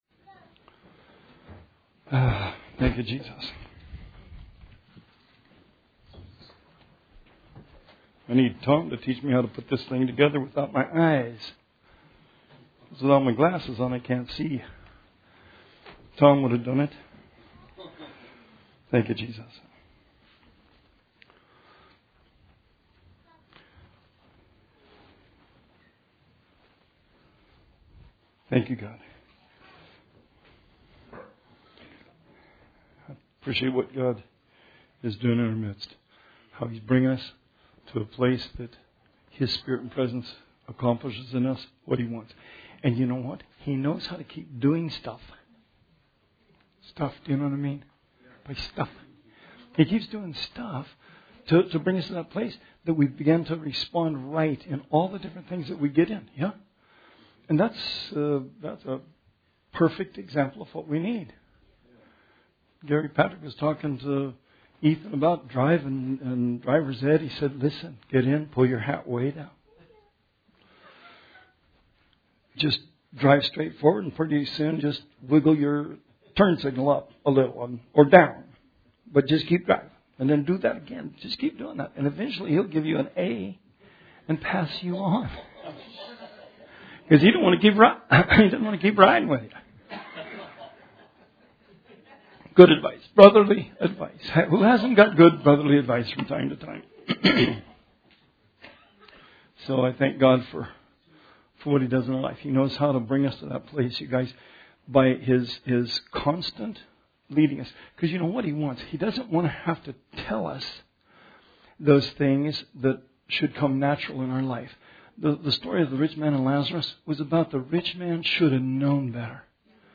Bible Study 2/20/19